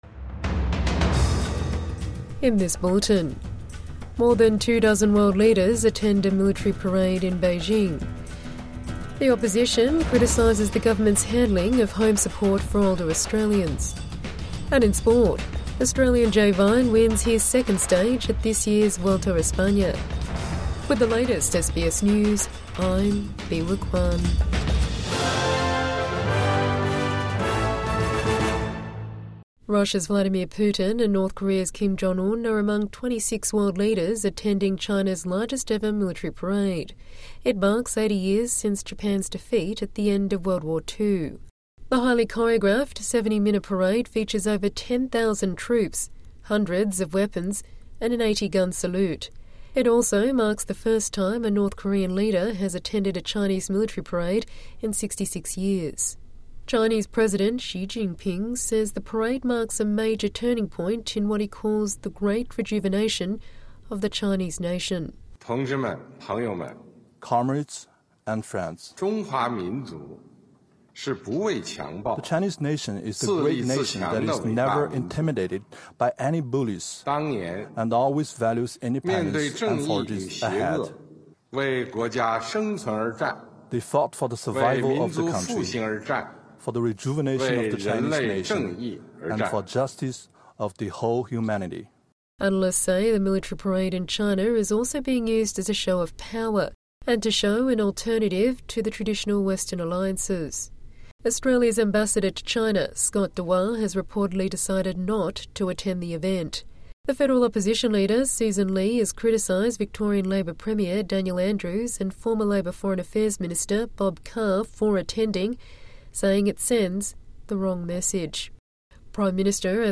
China's military parade attended by 26 world leaders | Midday News Bulletin 3 Sep 2025 5:04